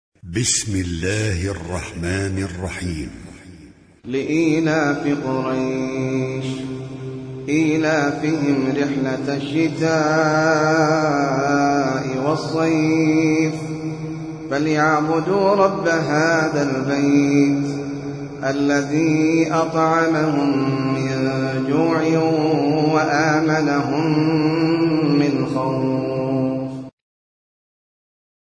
Sûrat Quraish - Al-Mus'haf Al-Murattal (Narrated by Hafs from 'Aasem)
high quality